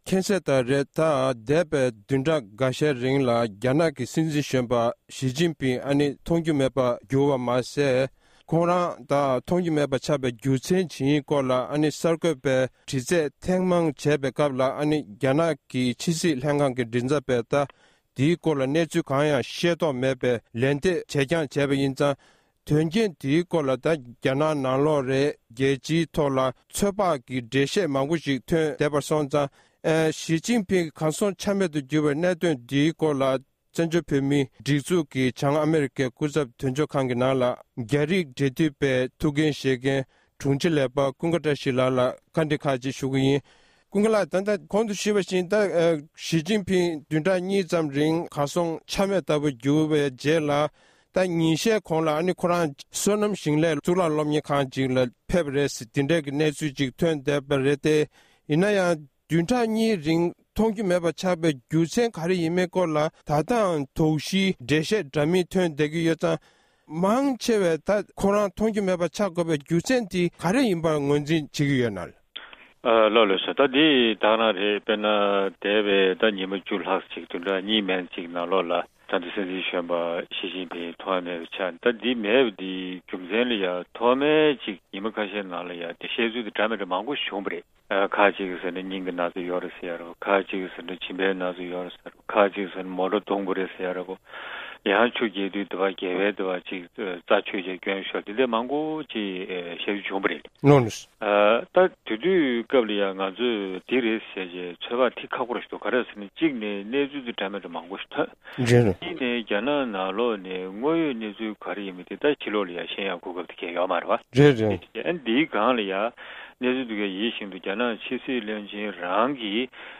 གནས་འདྲི་ཞུས་པ་ཞིག